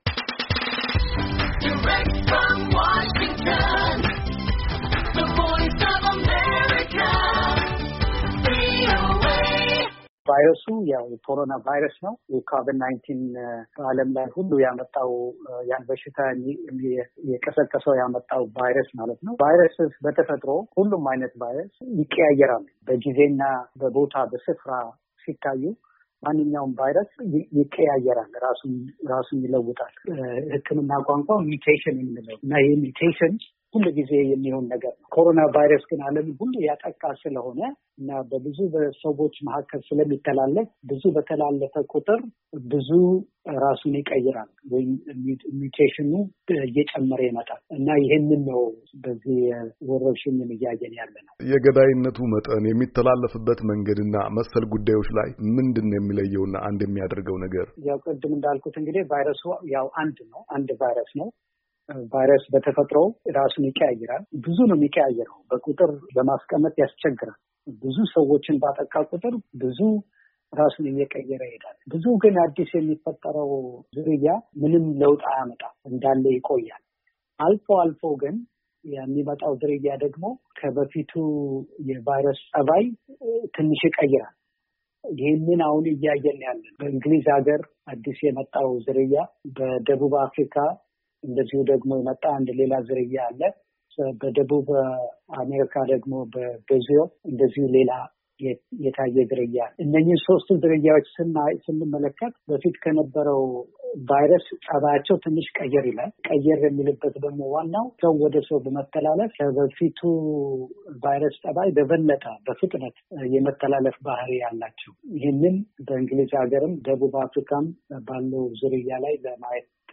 ቆይታ